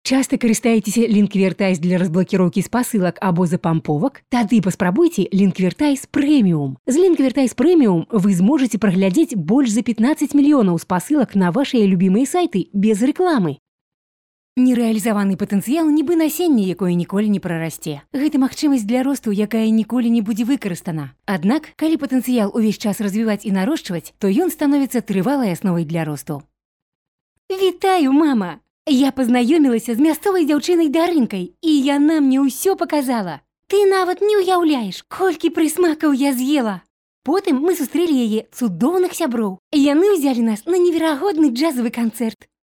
Пример звучания голоса
Жен, Рекламный ролик/Средний
AKG P420, Yamaha AG03, акустический экран.